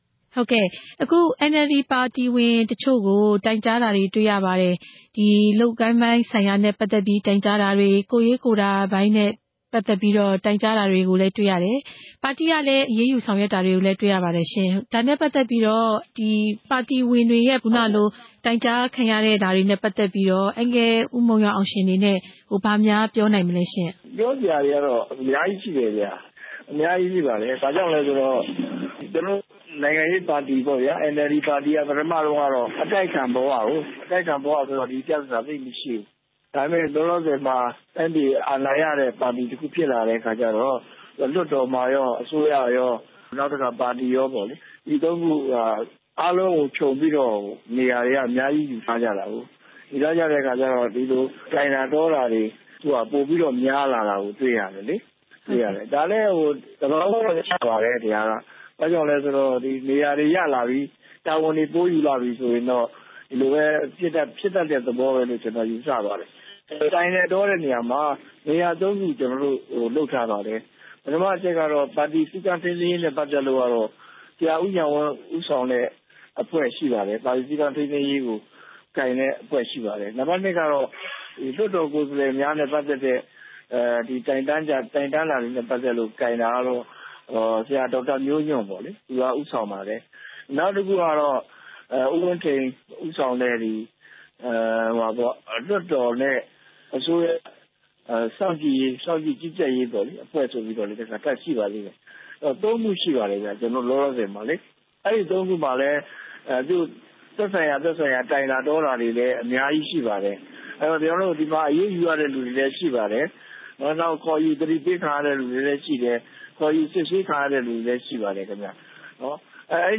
NLD ပါတီဝင်တွေ တိုင်ကြားခံရမှု မုံရွာအောင်ရှင်နဲ့ မေးမြန်းချက်